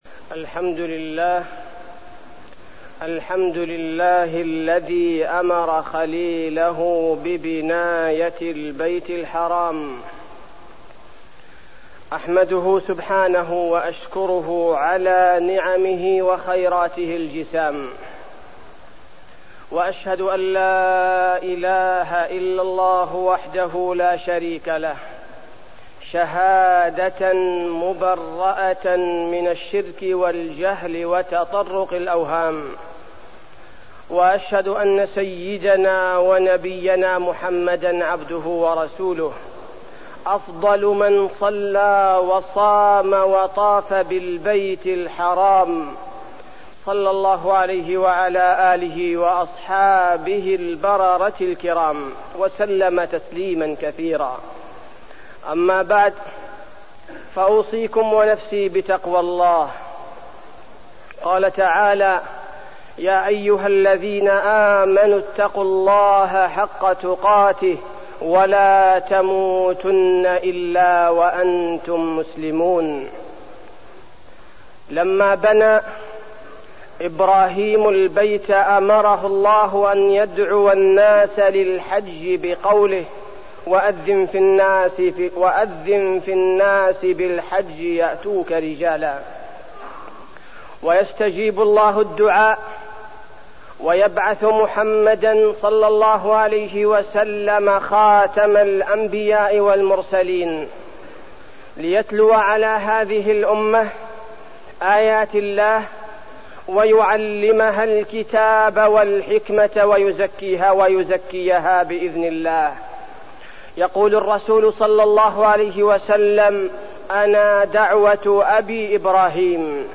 تاريخ النشر ٦ ذو الحجة ١٤١٨ هـ المكان: المسجد النبوي الشيخ: فضيلة الشيخ عبدالباري الثبيتي فضيلة الشيخ عبدالباري الثبيتي دروس من قصة إبراهيم عليه السلام في الحج